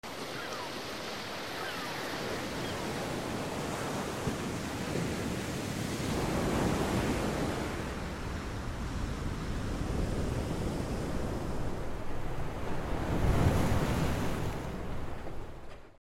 Let ocean sounds calm your sound effects free download
🎧🌊 As a nature sound recordist, I spent time along the Atlantic Ocean coastline in Portugal capturing what might be nature’s most relaxing sounds. This short video takes you through four different coastal spots, each with its own peaceful ocean soundscape. You’ll hear the soft ocean waves sounds rolling onto the beach, the calming seaside sound blending with the distant ocean ambience, powerful waves crashing against the cliffs and seagulls squawking above the dunes.
This is an authentic field recording from Niro Sound Lab, recorded without loops or AI – just pure sounds of nature, ocean waves, and coastal ambience for your daily calm.